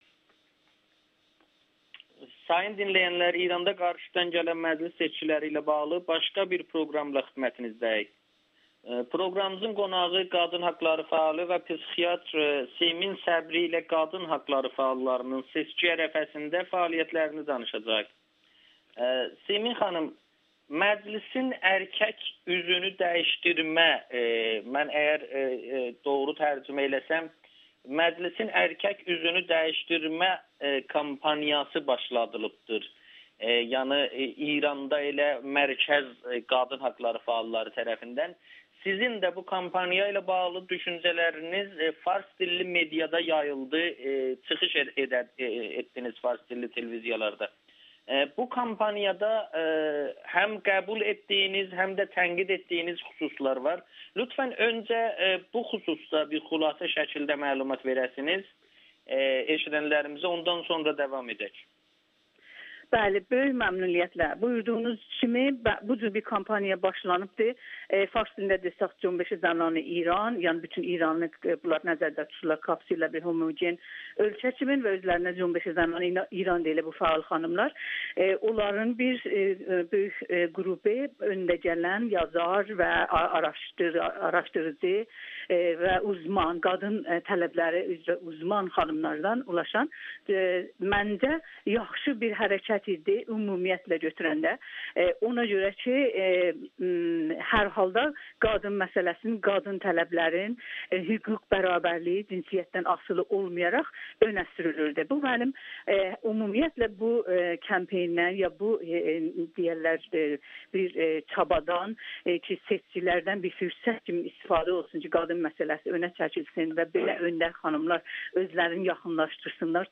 Güneyli fəal Amerikanın Səsinə danışıb